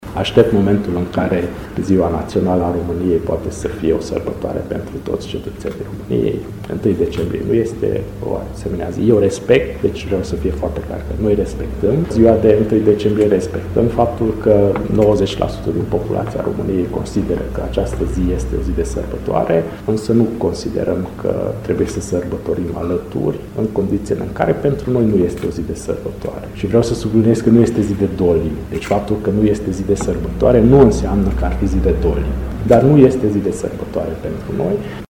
Primarul Antal Arpad, care este şi preşedintele UDMR Sfântu Gheorghe: